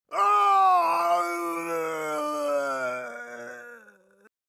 PLAY RoboCop Mk11 Scream